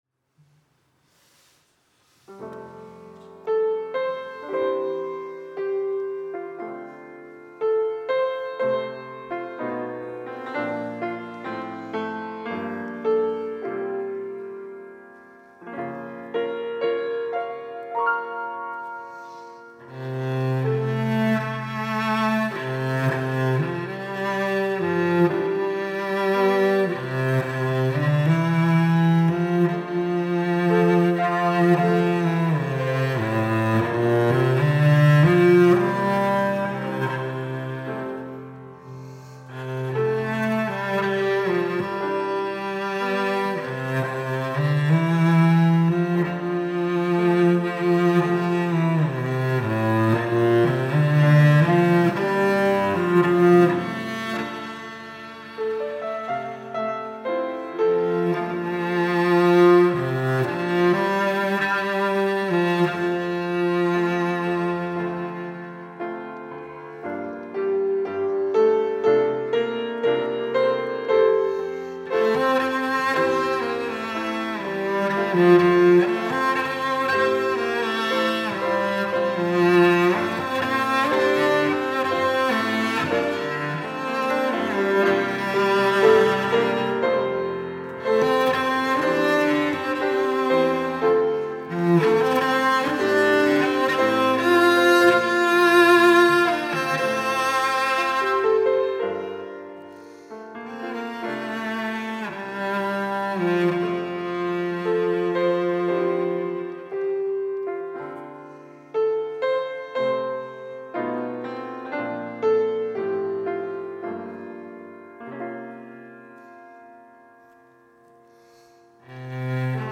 특송과 특주 - 광야를 지나며